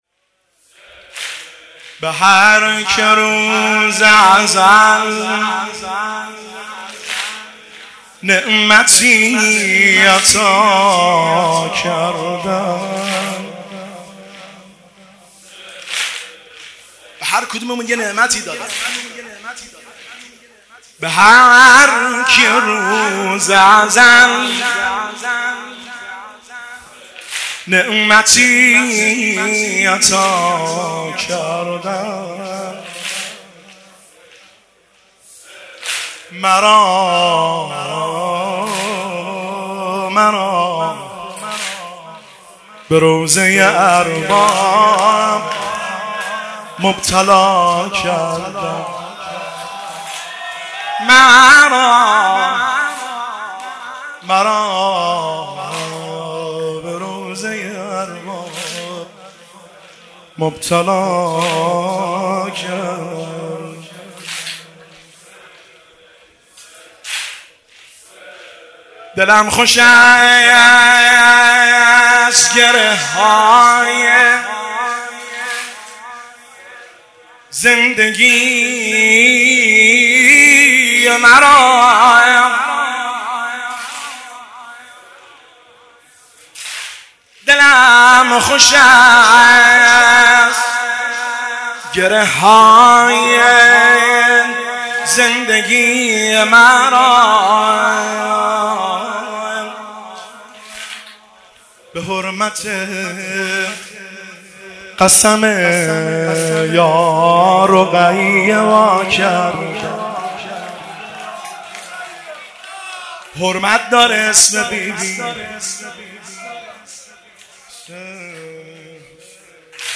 هیئت عاشقان قمر بنی هاشم تهران